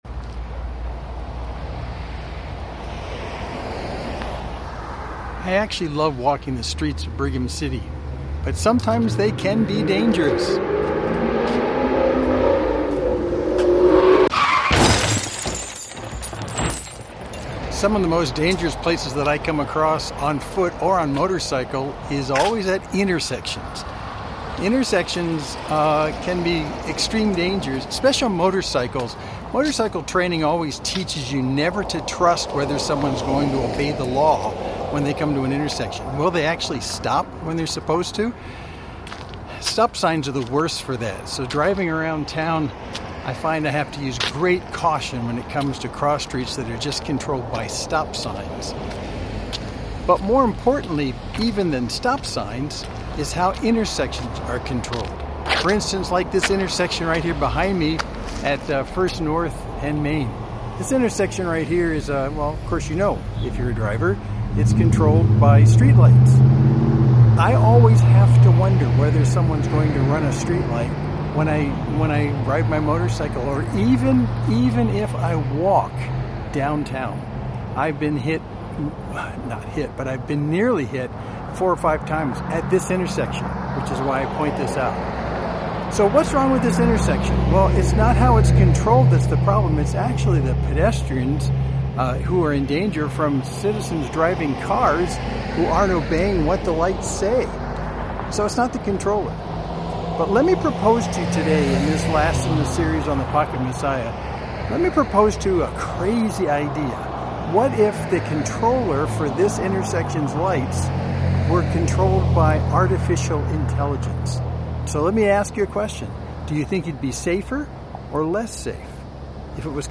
Main Street Church Sermon (17.45 - )